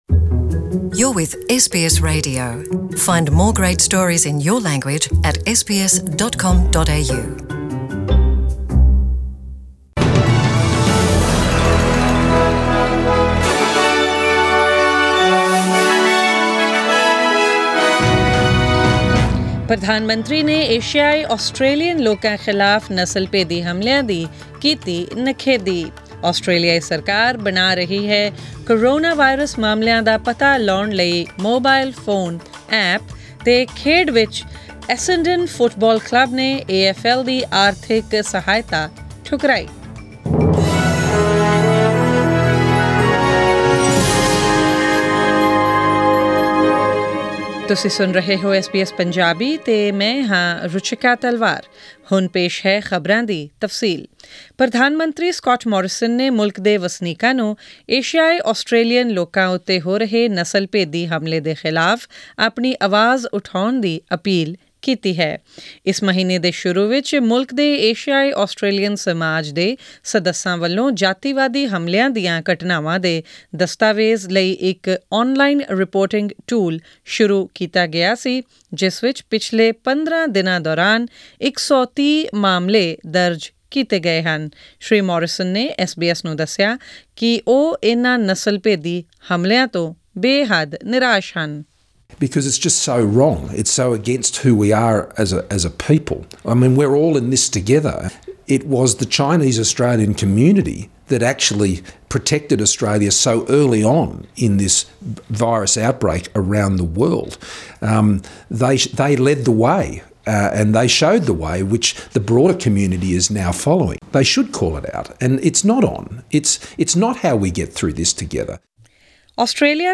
Australian News in Punjabi: 14 April 2020